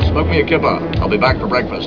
Terrible guitar noise "Yeah, the axe man is back"